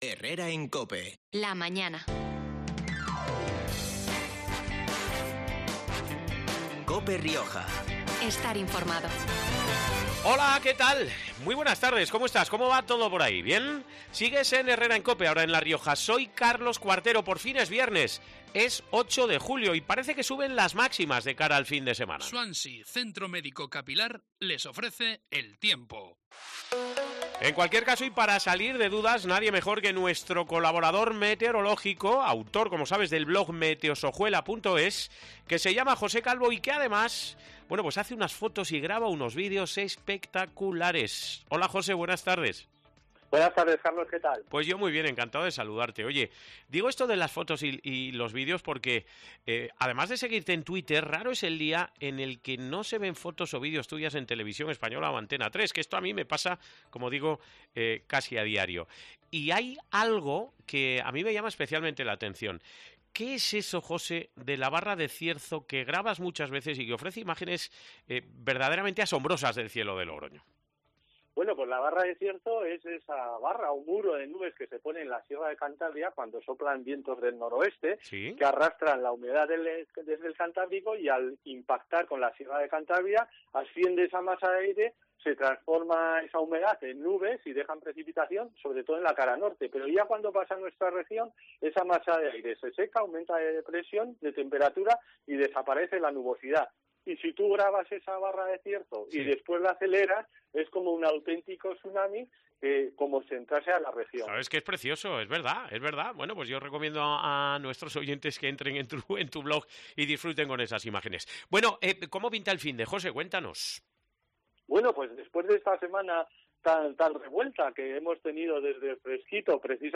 en los micrófonos de COPE Rioja